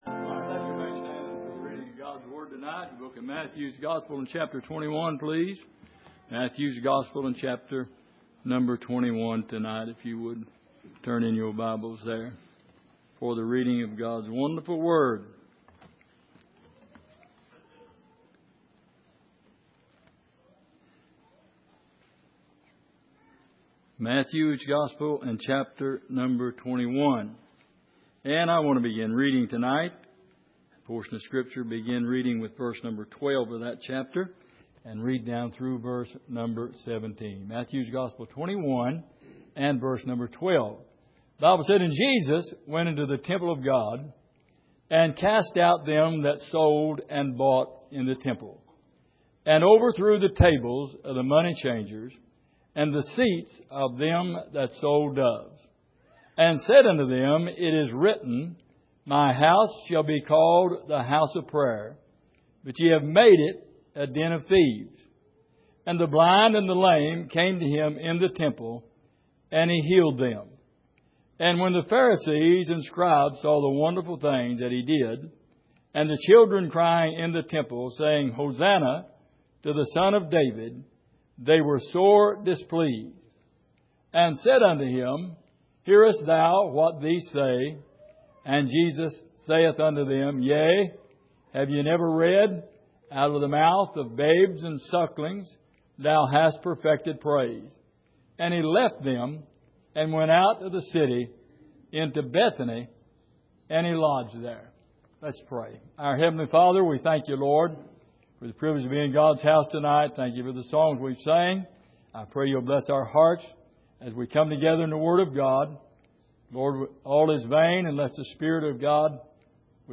Miscellaneous Passage: Matthew 21:12-17 Service: Sunday Evening